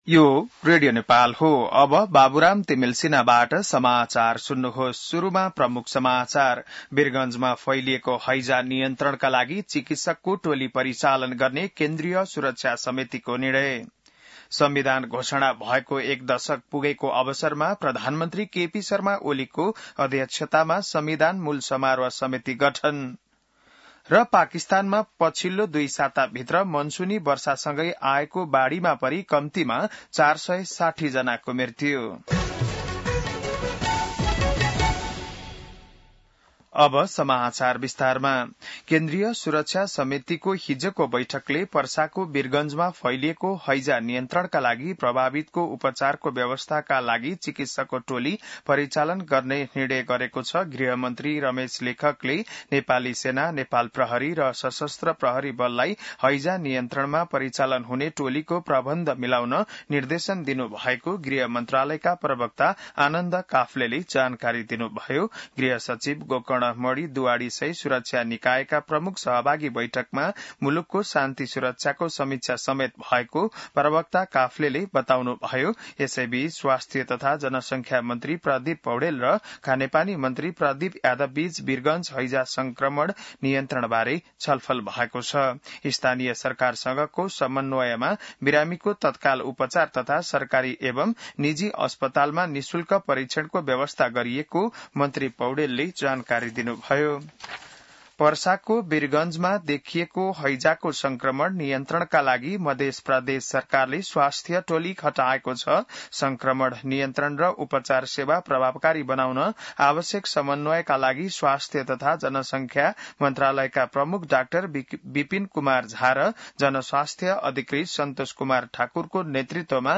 बिहान ९ बजेको नेपाली समाचार : ९ भदौ , २०८२